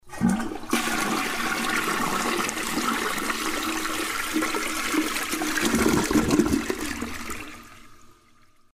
Звуки канализации
На этой странице собраны разнообразные звуки канализации: от тихого бульканья воды до резонанса в трубах.